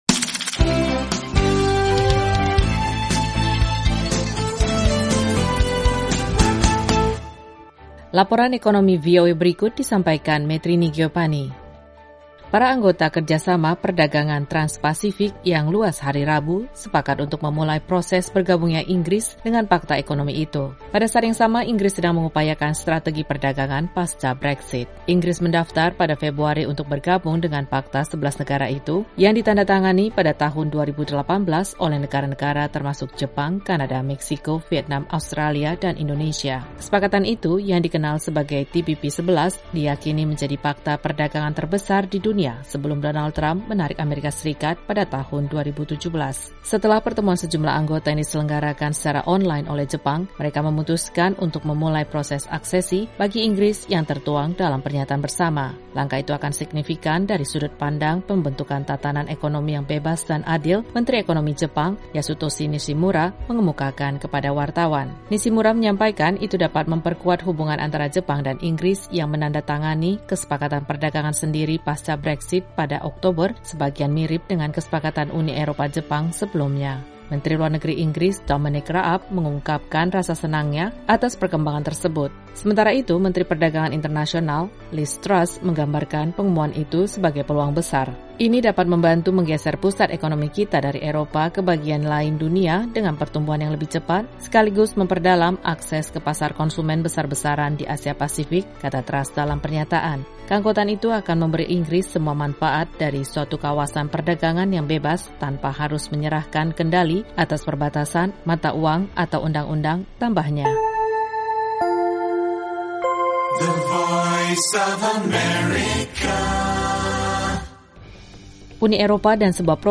Laporan Ekonomi VOA kali ini mengetengahkan pembicaraan yang dimulai terkait rencana Inggris bergabung dengan Pakta Perdagangan Trans-Pasifik. Simak juga laporan mengenai Uni Eropa dan Bill Gates yang menjanjikan dana tambahan untuk teknologi ramah lingkungan.